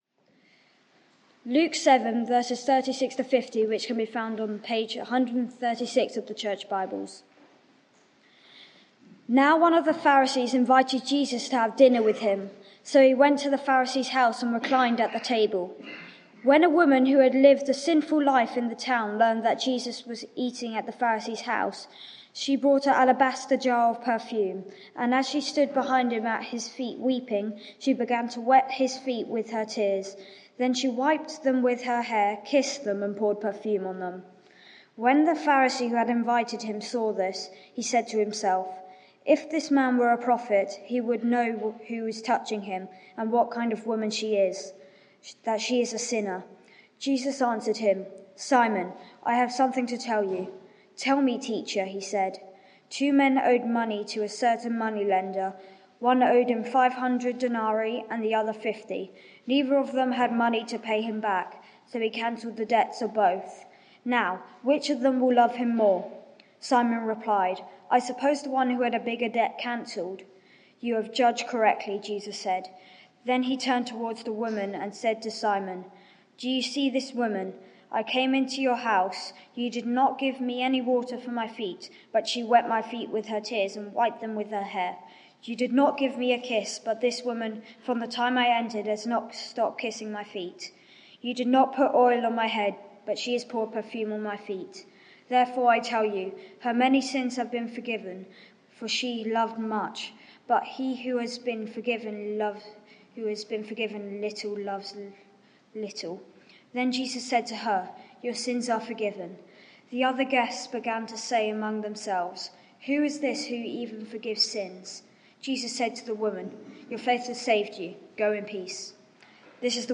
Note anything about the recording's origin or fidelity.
Media for 9:15am Service on Sun 03rd Jul 2022 10:45